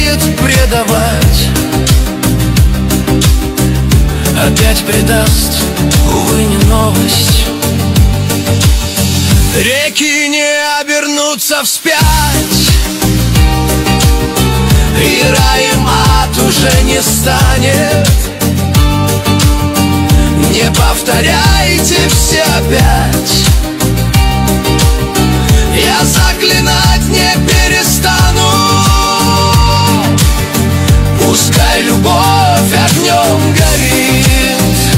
Жанр: Русские
Chanson in Russian